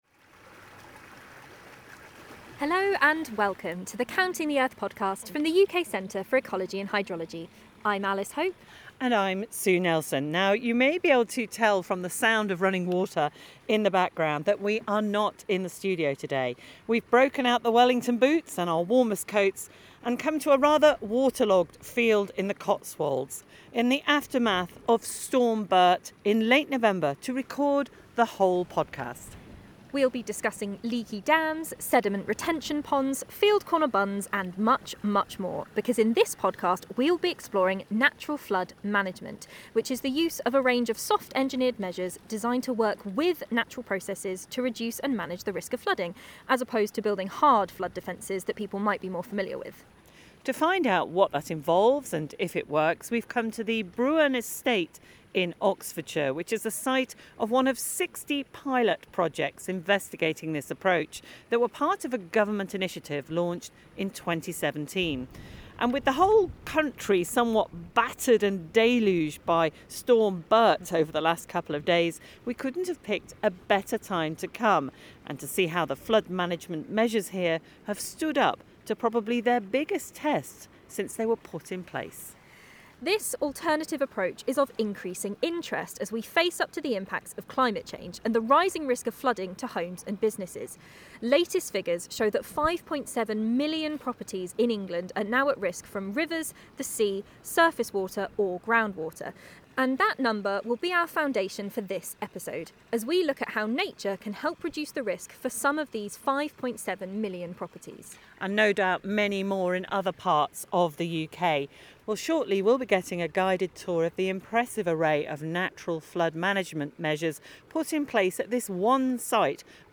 visit the Bruern Estate in Oxfordshire to explore how Natural Flood Management measures are reducing flood risks downstream in Milton-under-Wychwood.